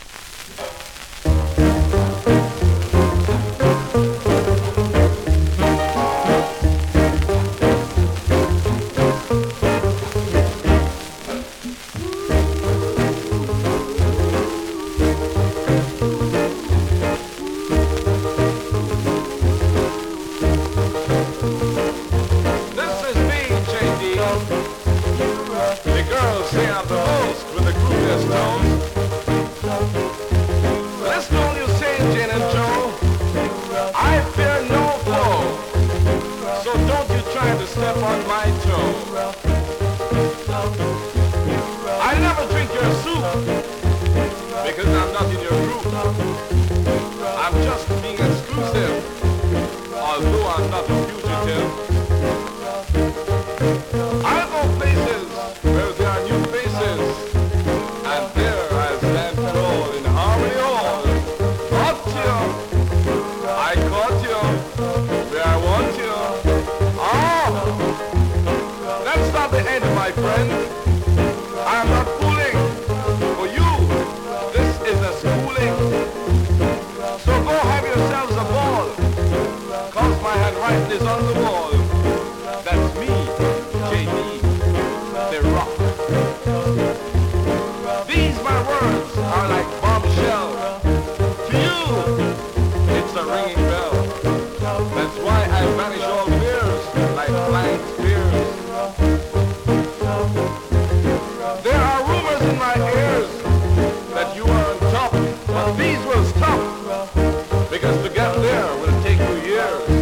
コメントレアJAMAICAプレス!!ROCKSTEADY!!
スリキズ、ノイズそこそこありますが